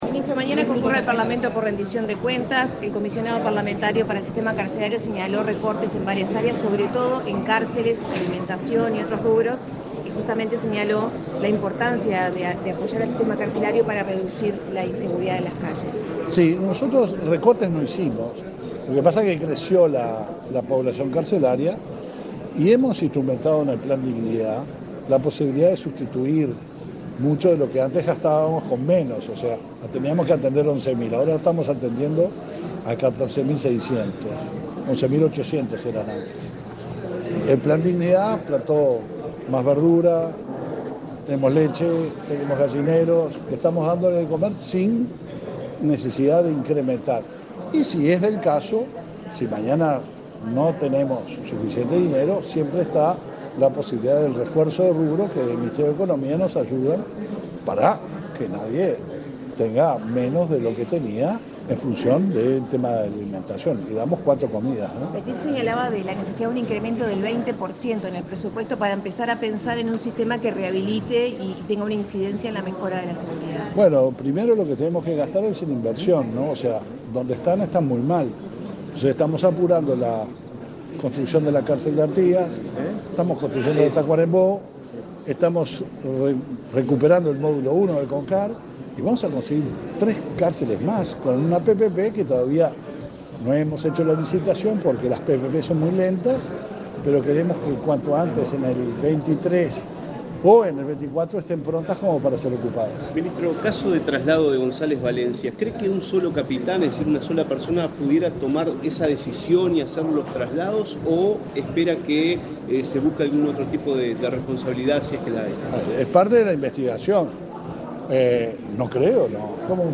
Declaraciones de prensa del ministro del Interior, Luis Alberto Heber
Tras el evento, efectuó declaraciones a la prensa.